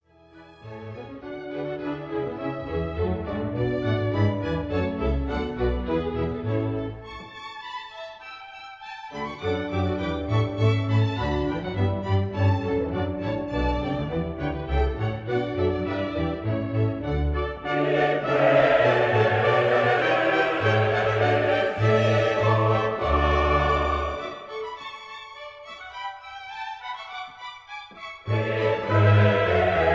soprano
contralto
tenor
bass
A stereo recording made in Walthamstow Assembly Hall 1958